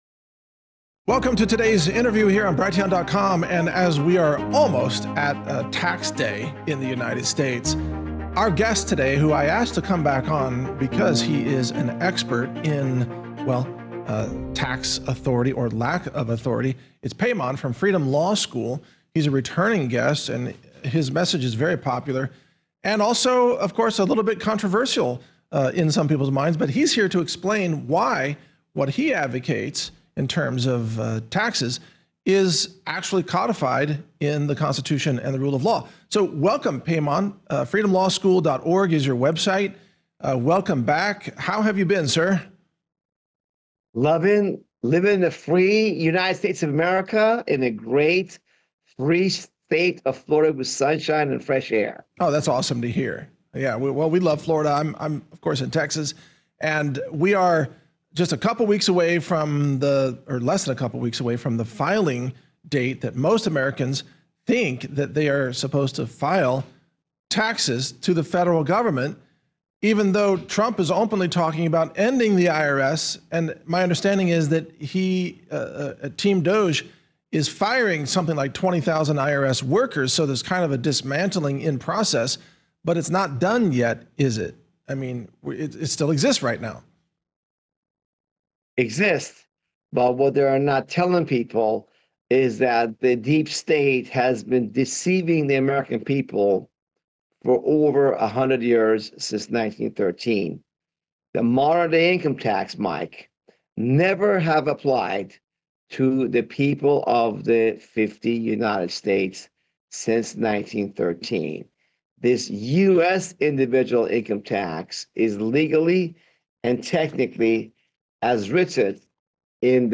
- Interview Introduction and Background (0:00)